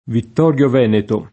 Vittorio [vitt0rLo] (ant. Vettorio [vett0rLo]) pers. m. — sim. i cogn. Vittorio, De Vittorio, Di Vittorio; e cfr. Vettori — anche top.: Vittorio Veneto [